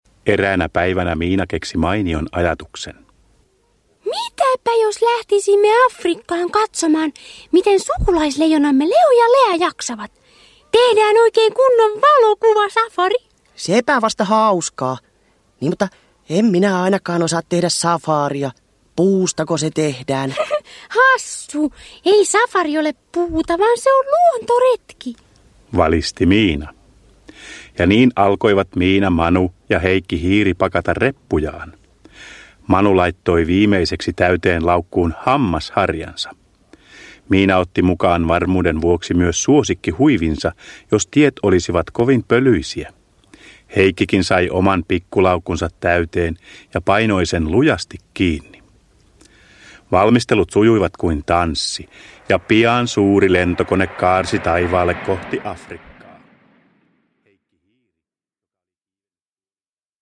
Miina ja Manu Afrikassa – Ljudbok – Laddas ner